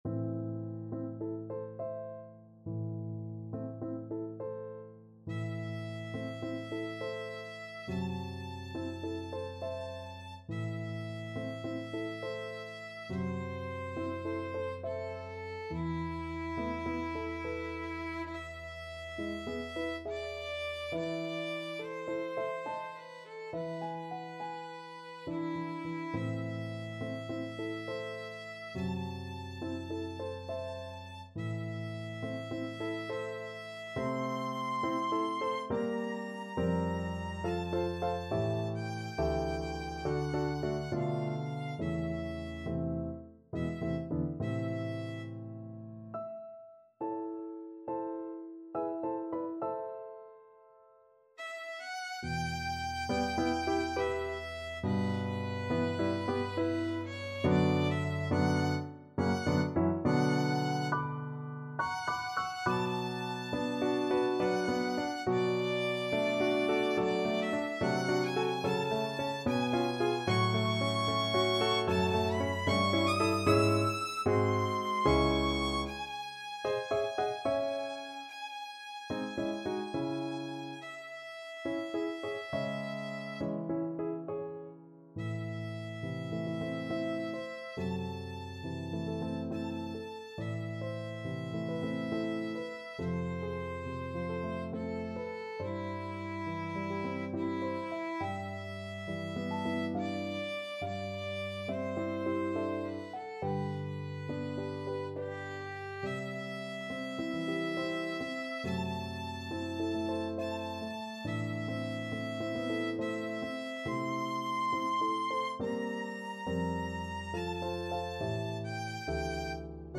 Violin version
Andante =69
3/8 (View more 3/8 Music)
Classical (View more Classical Violin Music)